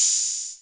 808-OpenHiHats09.wav